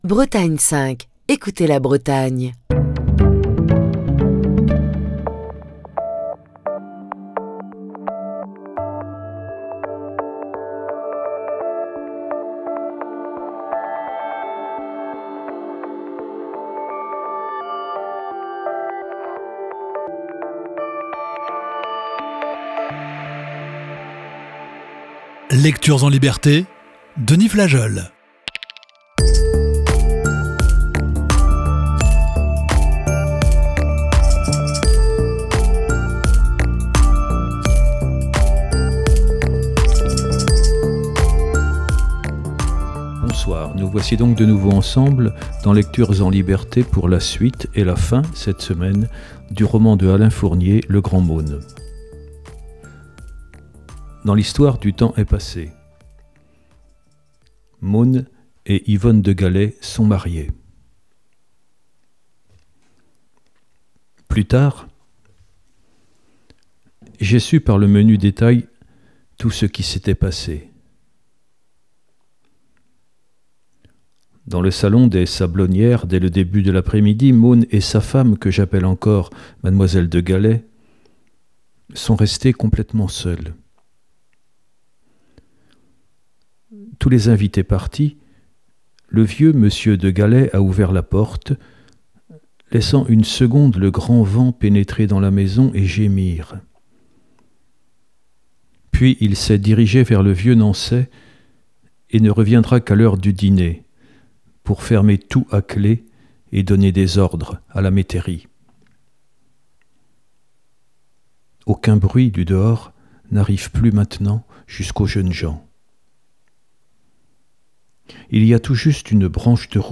Émission du 18 décembre 2023.